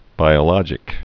(bīə-lŏjĭk)